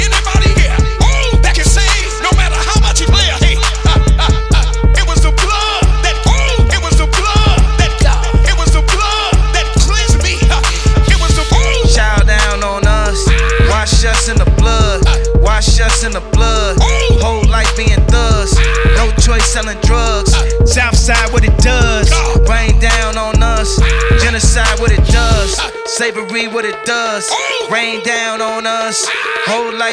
• Hip-Hop/Rap
features electro production